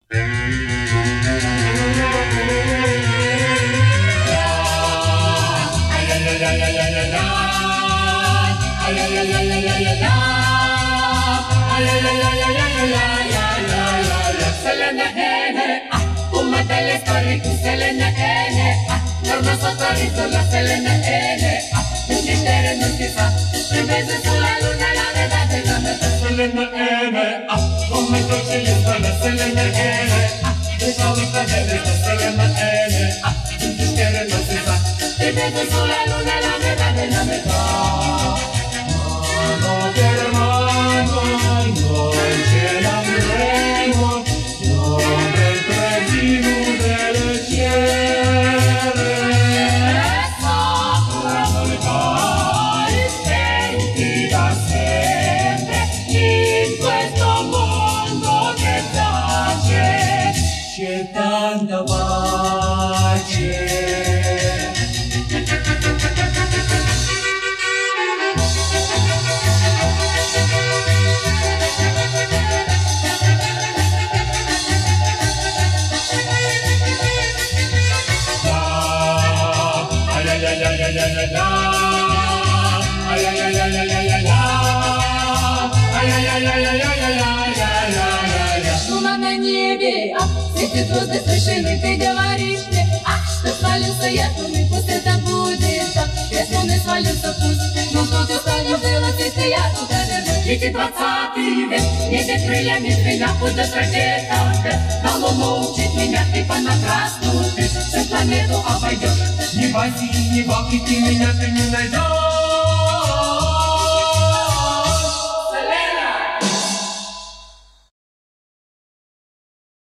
Замечательный квартет. Прекрасный квартет.
вокального квартета